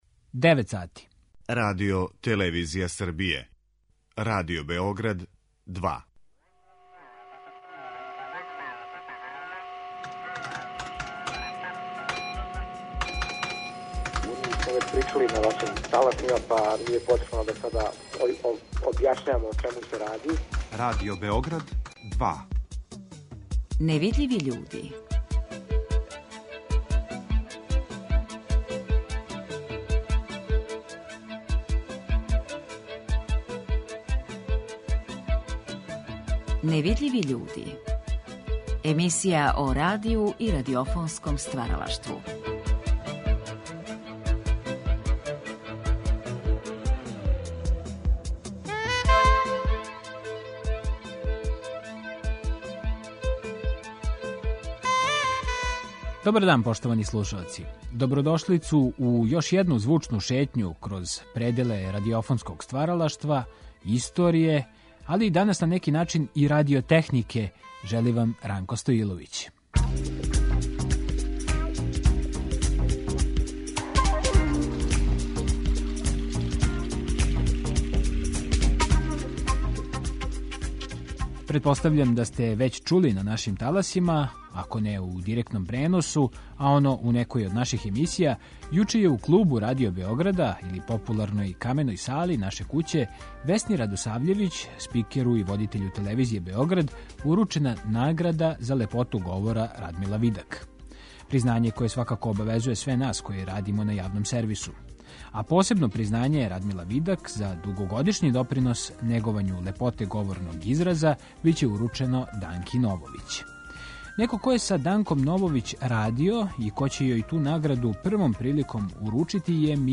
Наш гост је музичар, водитељ и забављач Миња Субота, по образовању дипломирани инжењер електротехнике, страствени колекционар старих радио-апарата, транзистора и микрофона.